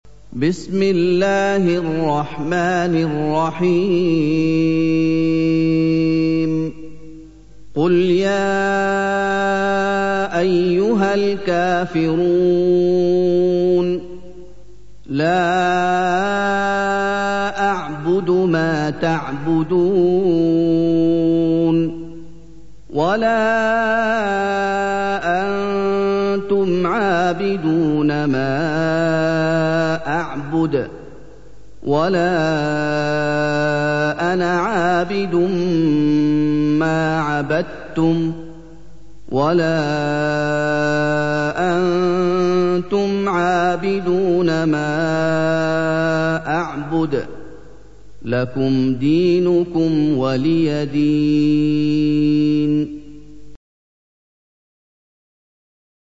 سُورَةُ الكَافِرُونَ بصوت الشيخ محمد ايوب